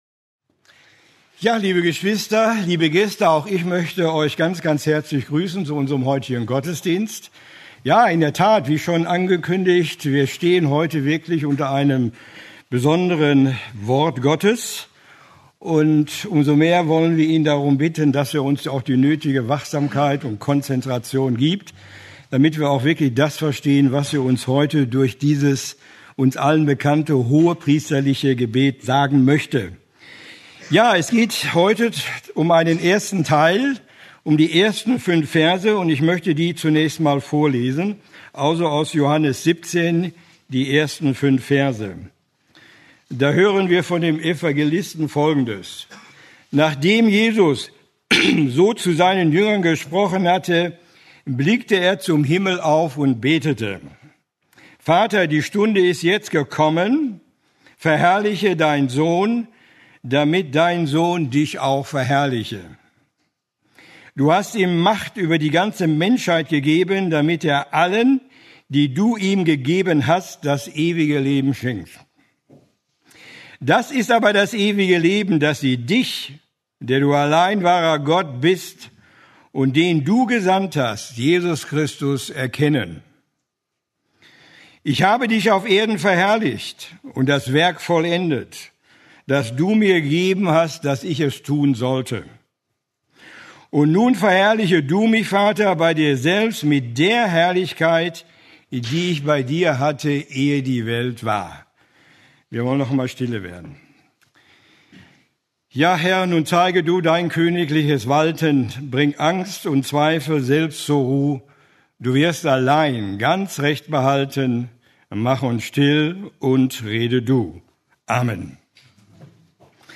Eine predigt aus der serie "Johannes Evangelium."